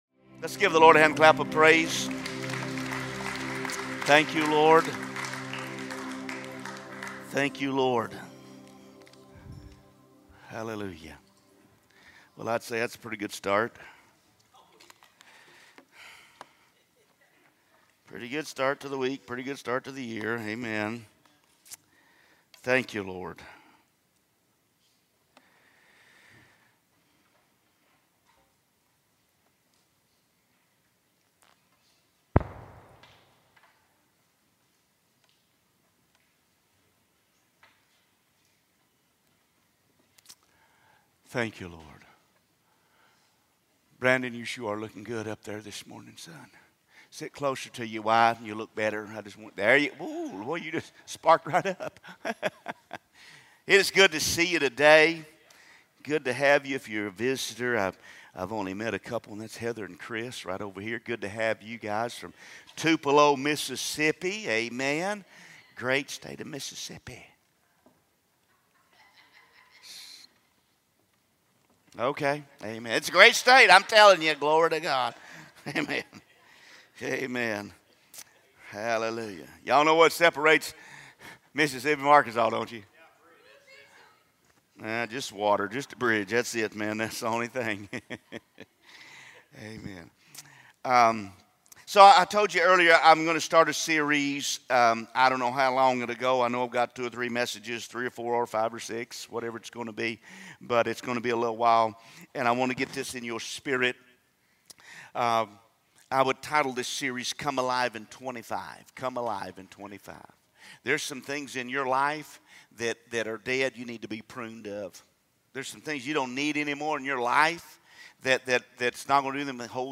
From Series: "Sunday Message"